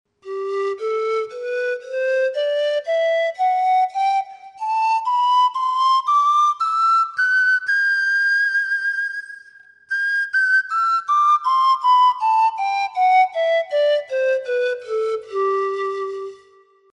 Панфлейта UU-15 левосторонняя
Панфлейта UU-15 левосторонняя Тональность: G
Материал: пластик ABS. Диапазон - две октавы (соль первой – соль третьей), строй диатонический (соль мажор).